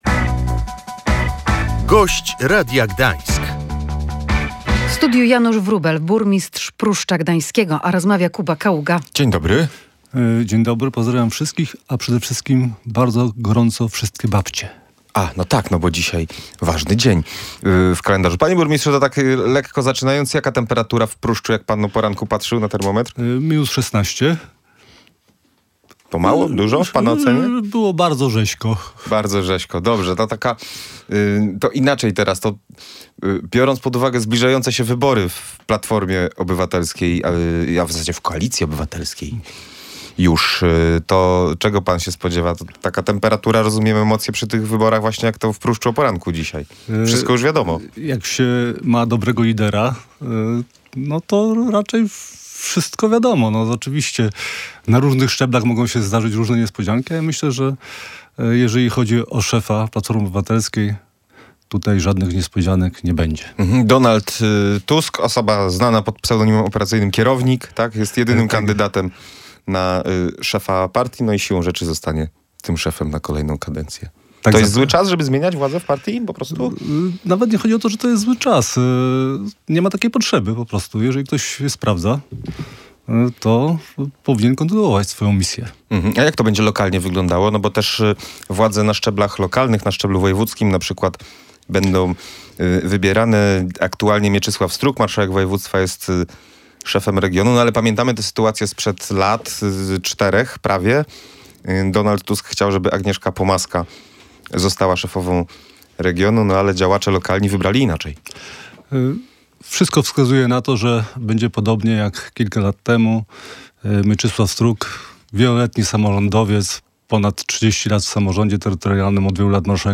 Nie ma potrzeby zmian, jeśli dobrze sprawują swoją funkcję – mówił Gość Radia Gdańsk, burmistrz Pruszcza Gdańskiego Janusz Wróbel.